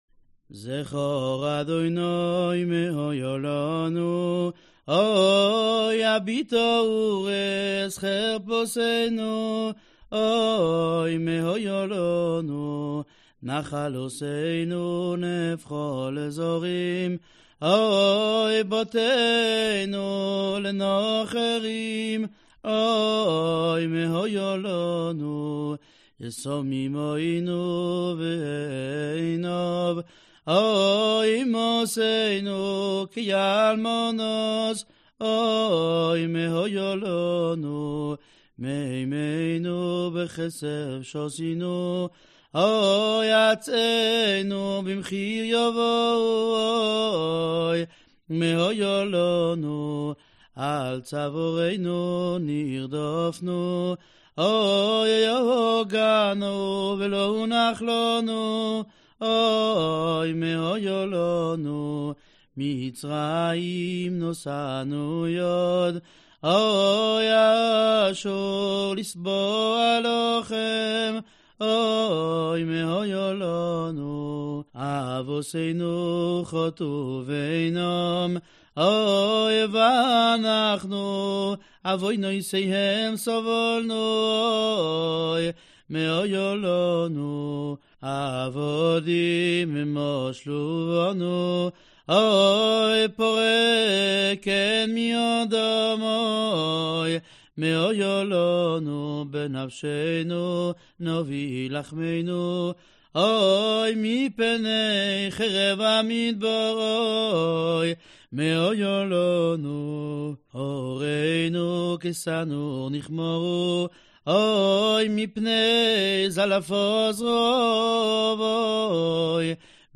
Траурные элегии, которые исполняют в память о разрушении Храма